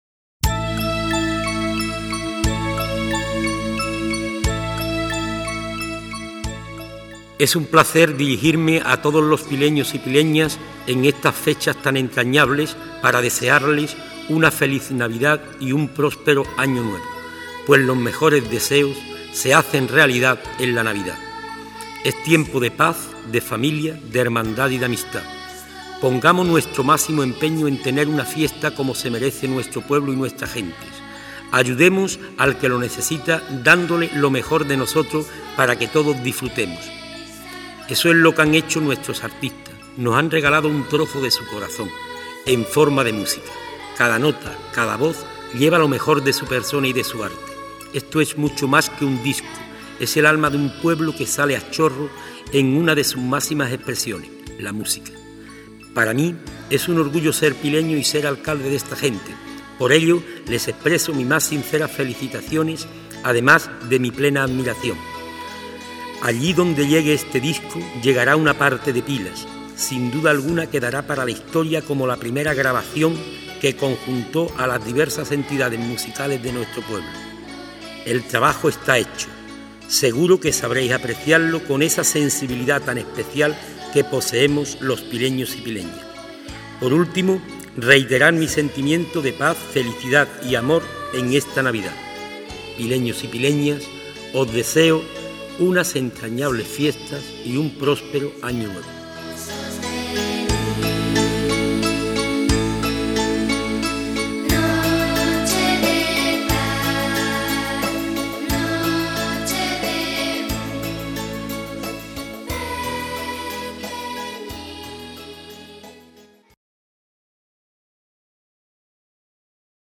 Mensaje Navideño de D.Jesus Calderón Moreno Alcalde de Pilas